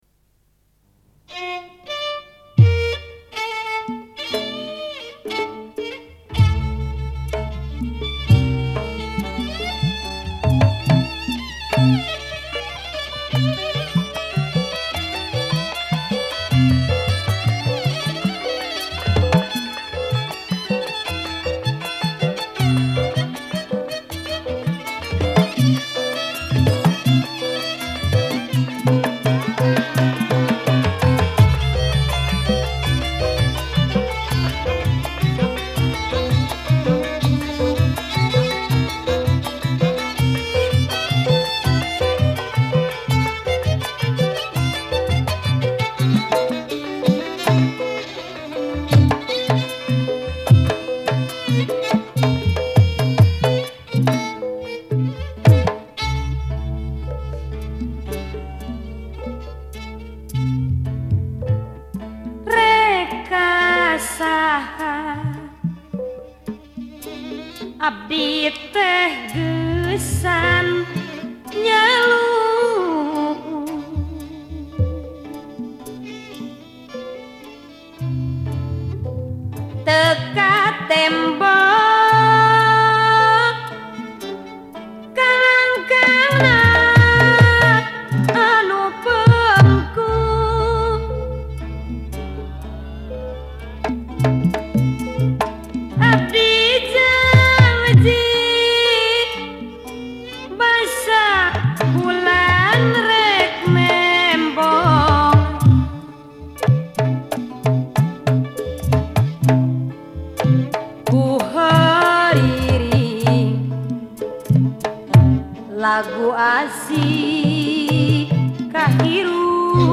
Pesinden